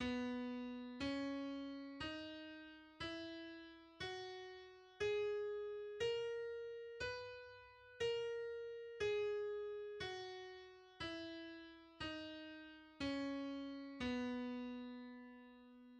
C-flat major is a major scale based on C, consisting of the pitches C, D, E, F, G, A, and B. Its key signature has seven flats.
The C-flat major scale is: